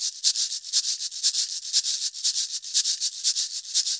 Cabasa_ ST 120_1.wav